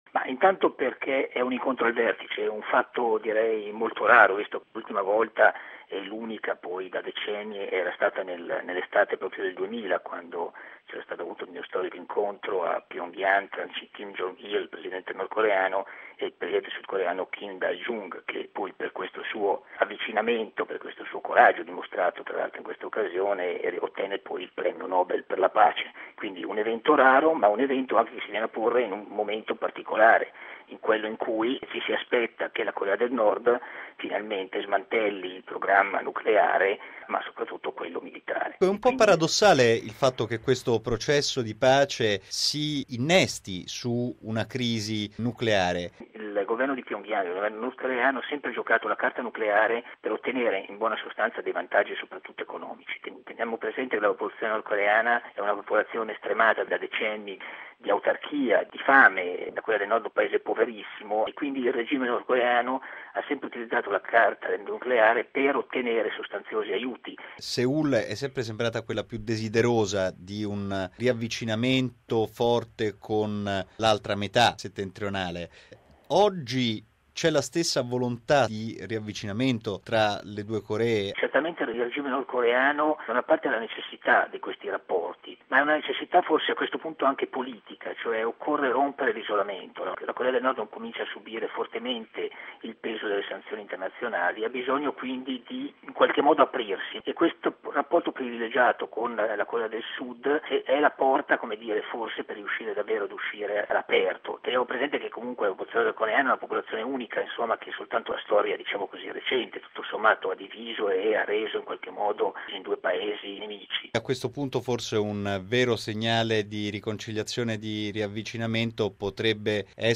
esperto di questioni asiatiche